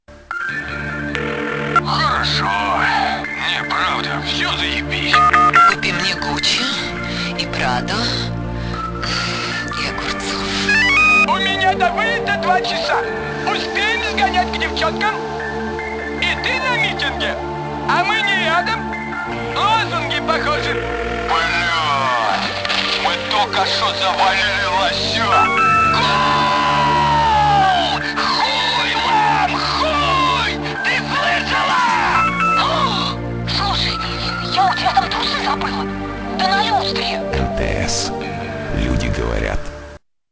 Пародия на рекламу МТС
ВНИМАНИЕ содержиться ненармотивная лексика!!!